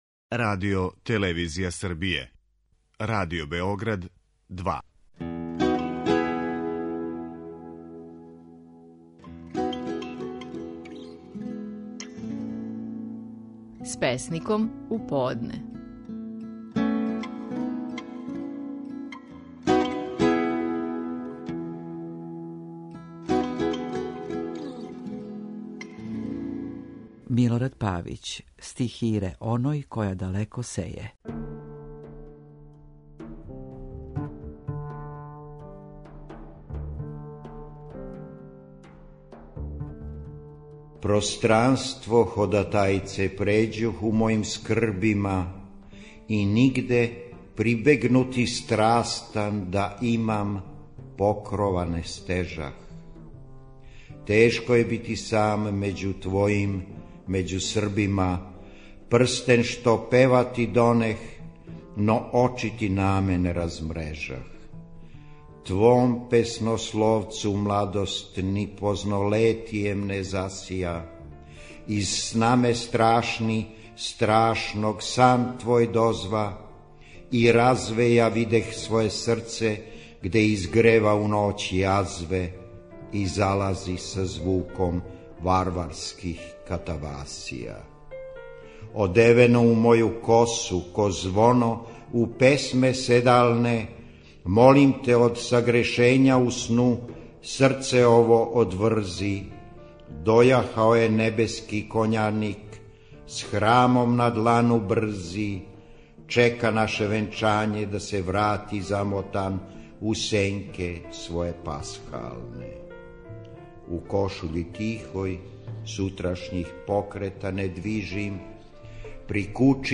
Стихови наших најпознатијих песника, у интерпретацији аутора.
Милорад Павић казује песму „Стихире оној која далеко сеје".